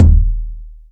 KICK.95.NEPT.wav